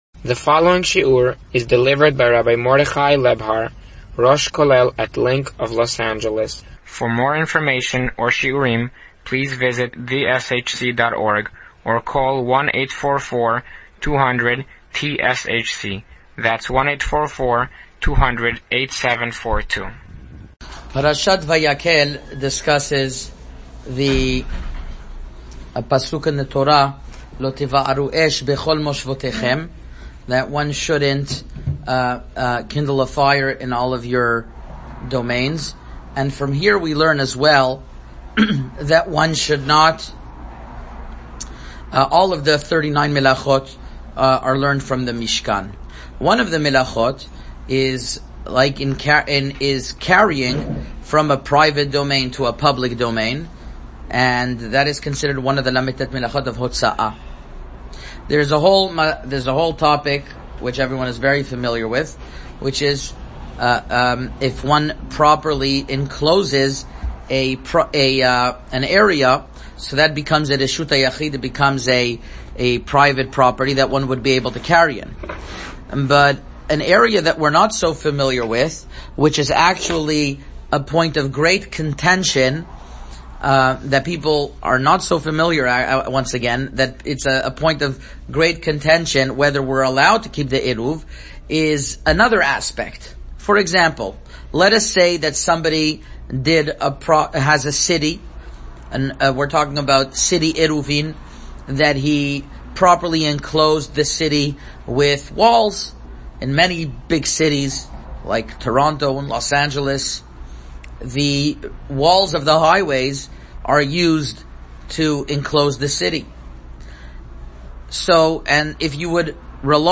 Parasha & Halacha Shiur Summary on Parashat VaYakhel